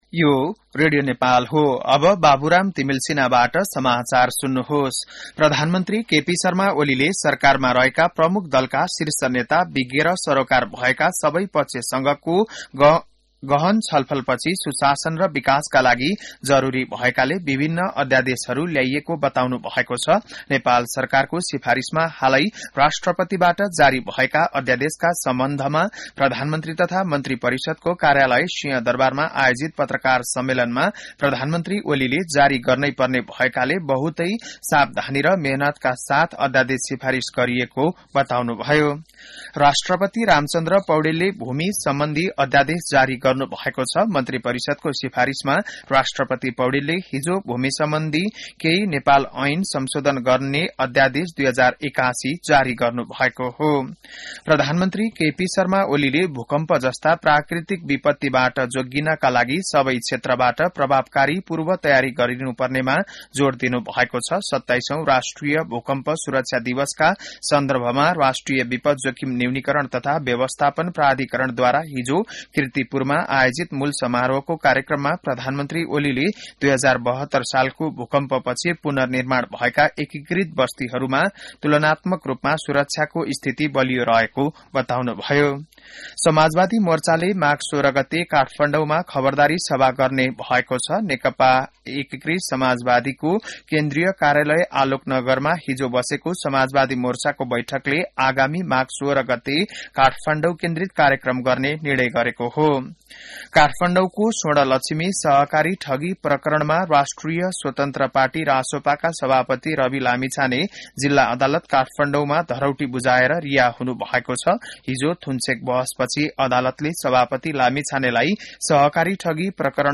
बिहान १० बजेको नेपाली समाचार : ४ माघ , २०८१